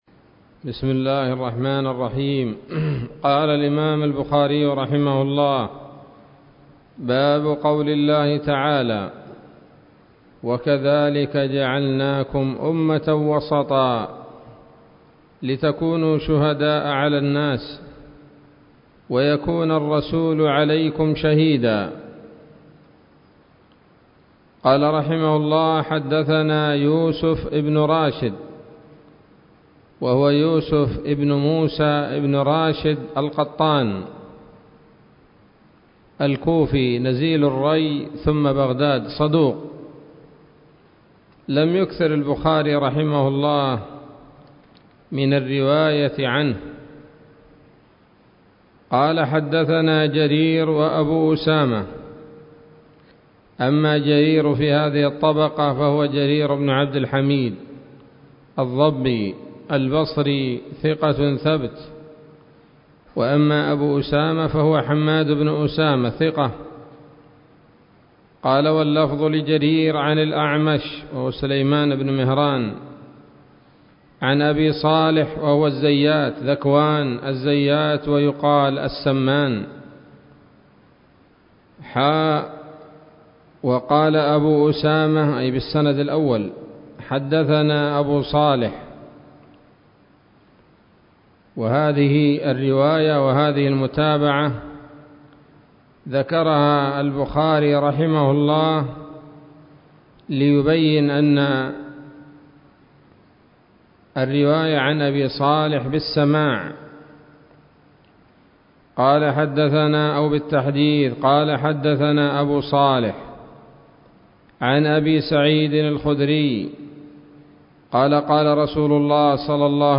الدرس الرابع عشر من كتاب التفسير من صحيح الإمام البخاري